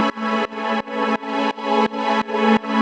Index of /musicradar/sidechained-samples/170bpm
GnS_Pad-dbx1:4_170-A.wav